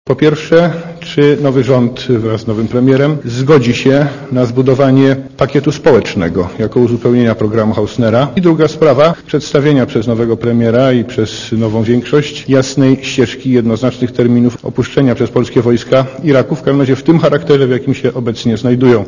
Komentarz audio Oceń jakość naszego artykułu: Twoja opinia pozwala nam tworzyć lepsze treści.